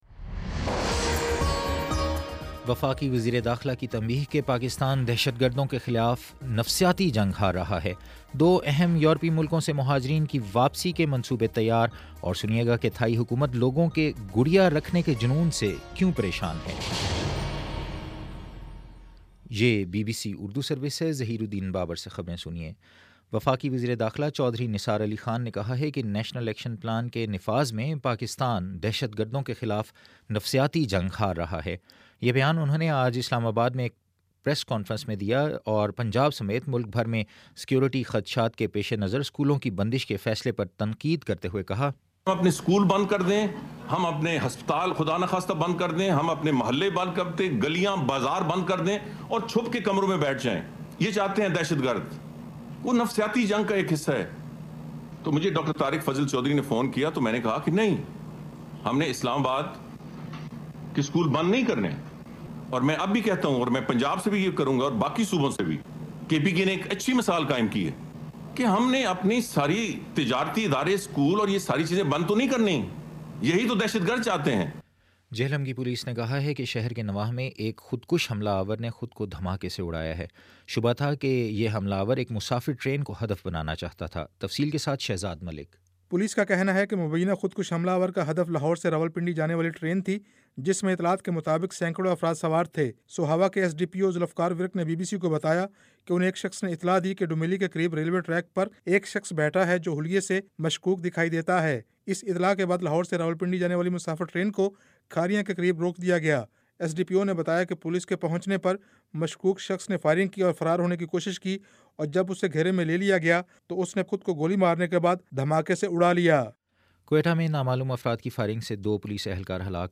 جنوری 28 : شام پانچ بجے کا نیوز بُلیٹن